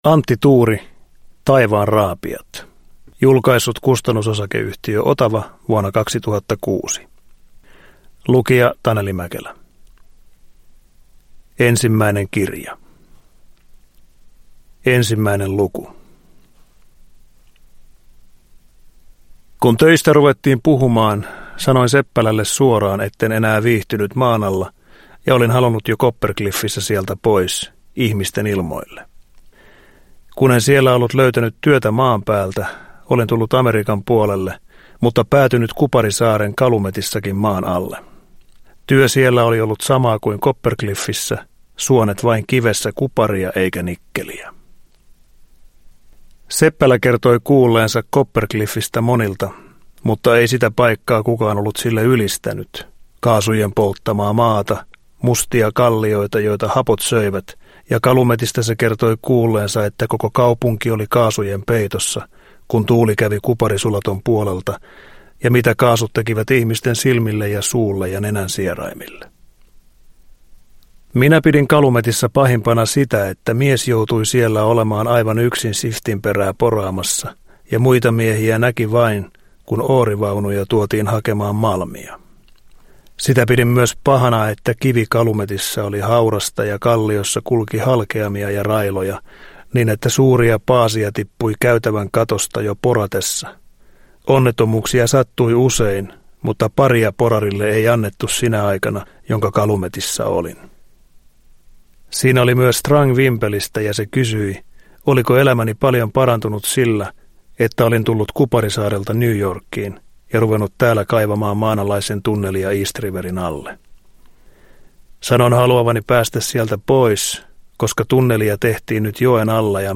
Taivaanraapijat – Ljudbok – Laddas ner
Uppläsare: Taneli Mäkelä